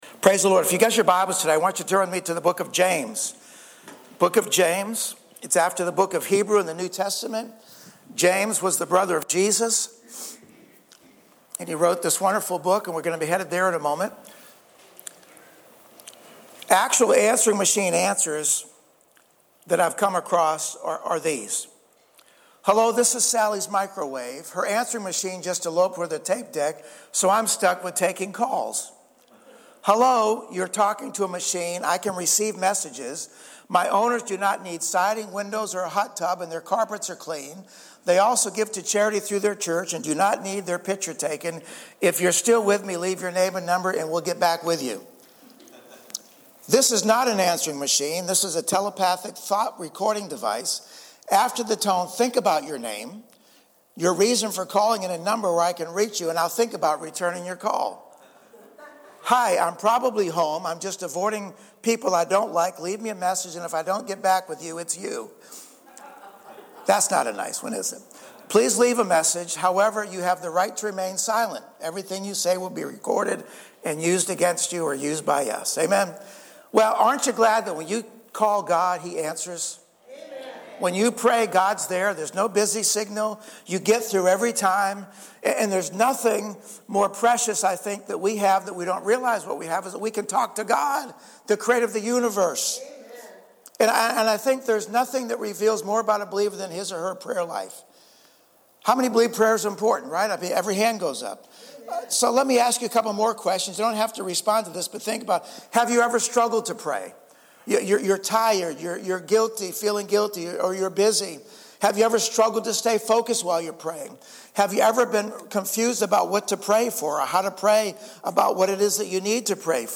Vision 2026 Series James prayer Sunday Morning Why is prayer so important?